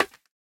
Minecraft Version Minecraft Version snapshot Latest Release | Latest Snapshot snapshot / assets / minecraft / sounds / block / bamboo / place1.ogg Compare With Compare With Latest Release | Latest Snapshot